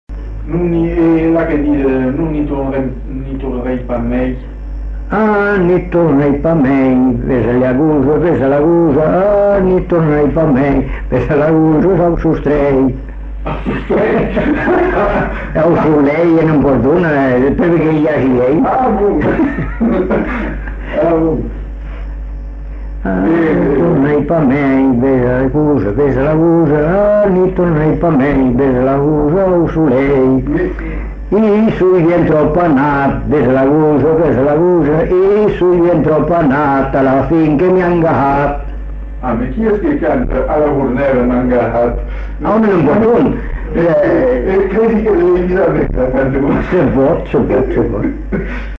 Aire culturelle : Bazadais
Lieu : Bazas
Genre : chant
Effectif : 1
Type de voix : voix d'homme
Production du son : chanté
Danse : rondeau